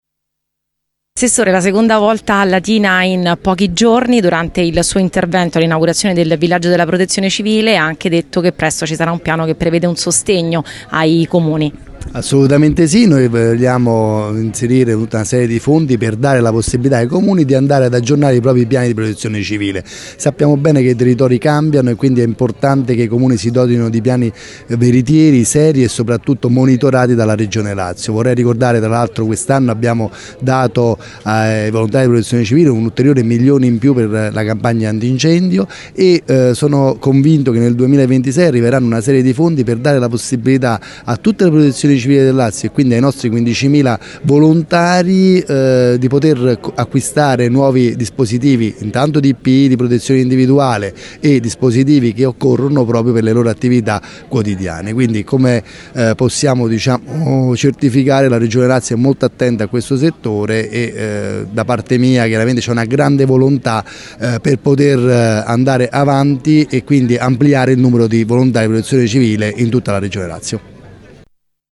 Al taglio del nastro dell’evento clou della Settimana della Protezione Civile, padrona di casa la sindaca di Latina Matilde Celentano, presenti la dottoressa Monica Perna Vicaria del Prefetto, l’assessore regionale Pasquale Ciacciarelli e l’assessore comunale Gianluca di Cocco.